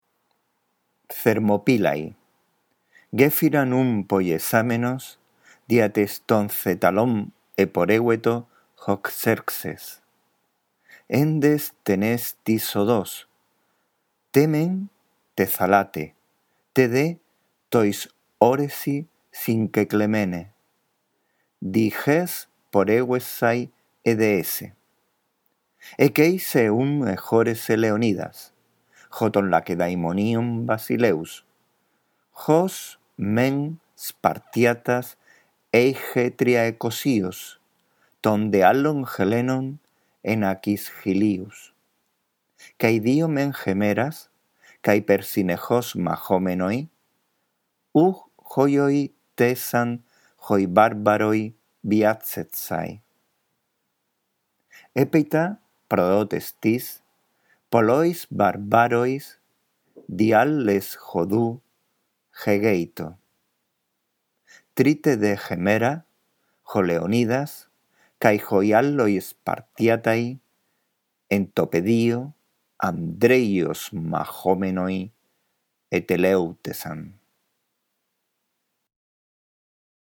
5. Lee, en primer lugar, despacio y en voz alta el texto.